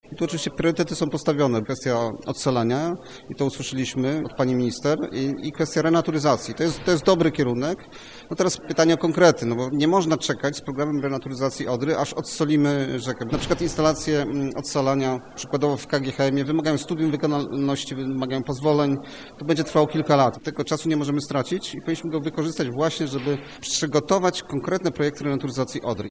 We Wrocławiu odbyło się także spotkanie minister z przyrodnikami, naukowcami, związkami wędkarskimi w zakresie niezbędnych działań dla Odry.